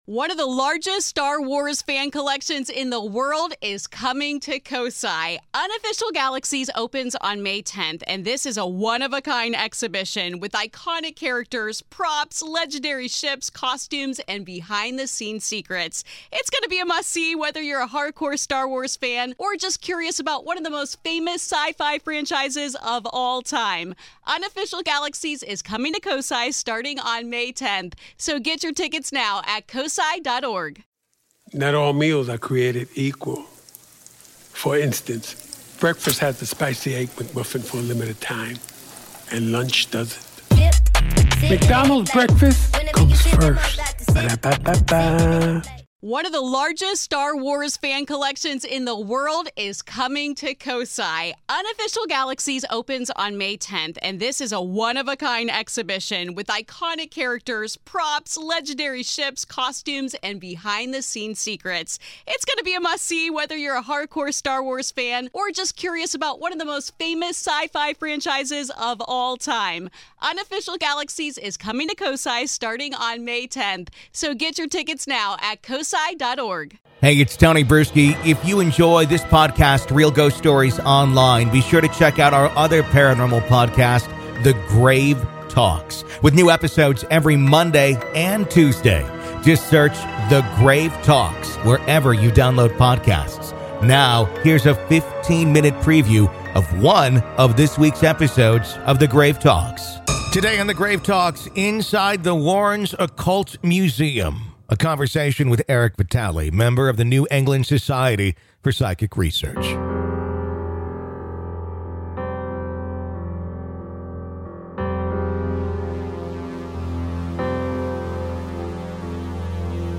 PART 2 - AVAILABLE TO GRAVE KEEPERS ONLY - LISTEN HERE In part two of our interview, available only to Grave Keepers , we discuss: What prevents Annabelle from teleporting out of its case today?